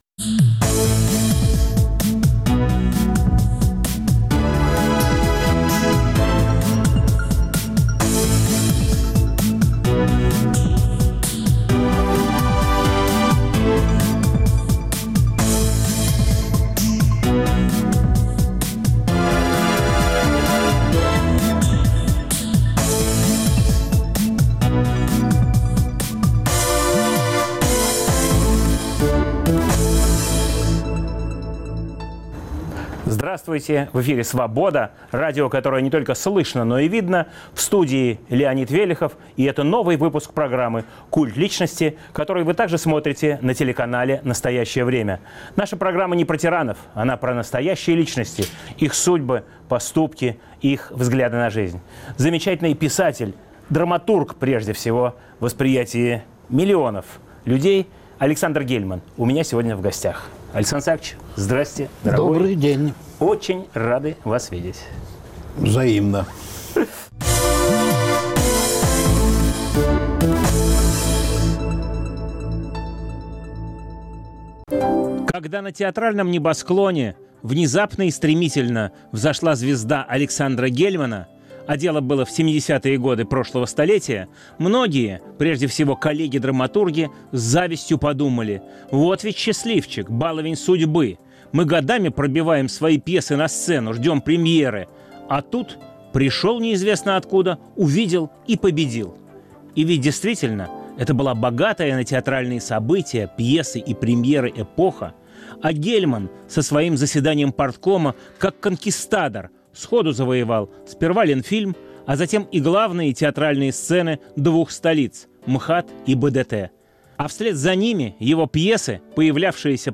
В студии драматург Александр Гельман.